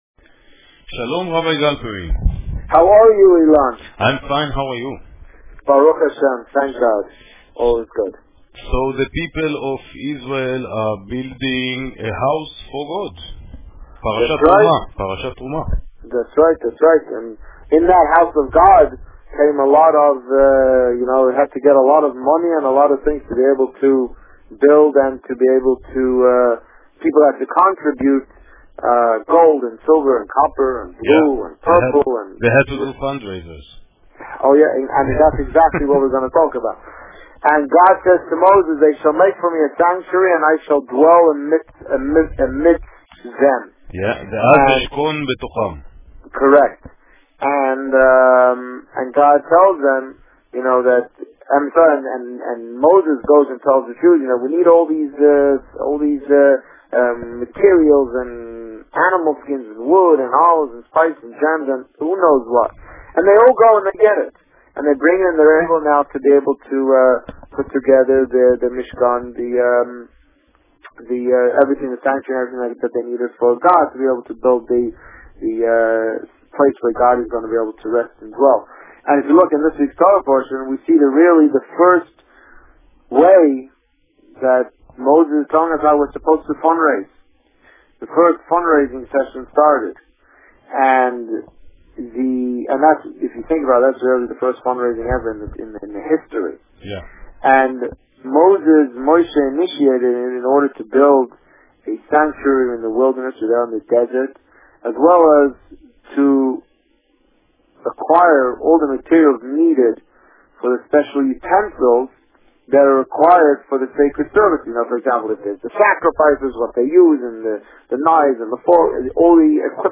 The Rabbi on Radio